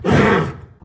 CosmicRageSounds / ogg / general / combat / creatures / horse / he / attack1.ogg